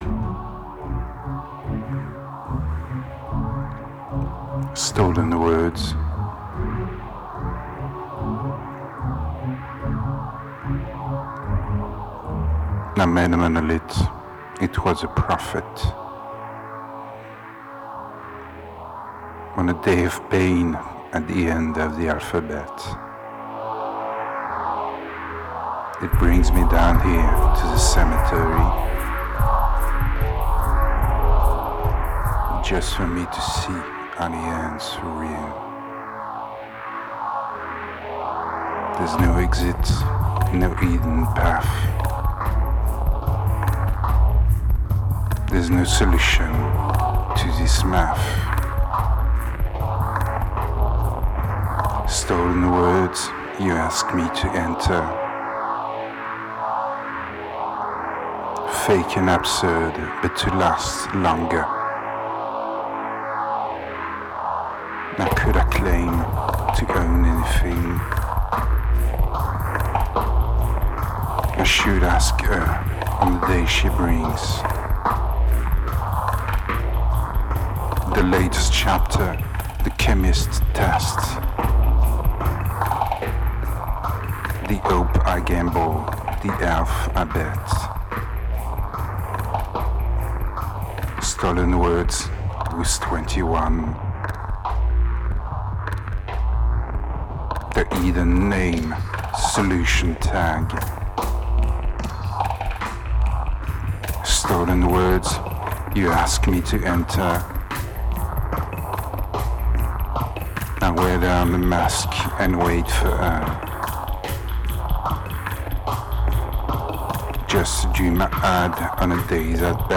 Tense and diverse